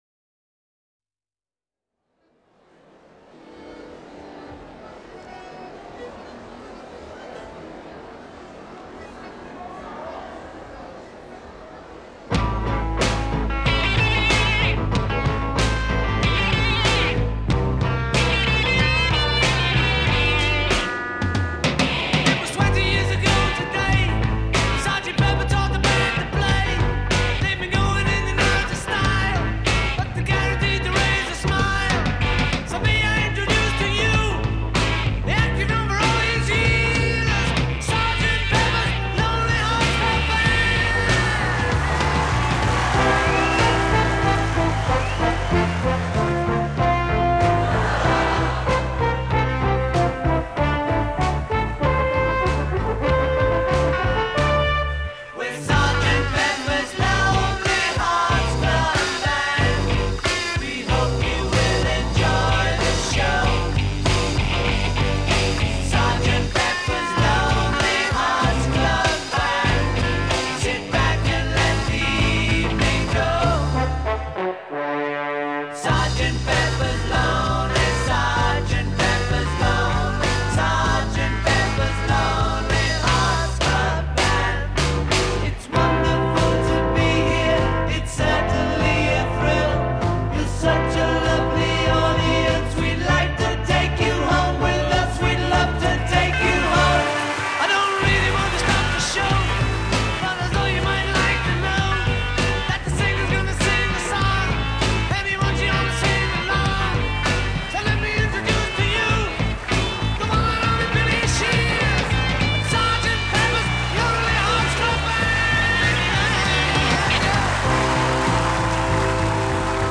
Begun 1 February 1967 (Studio Two).
Horn dubbing on 3 March.
horns
verse A   8 Solo voice over ensemble. d
bridge     2 drums and bass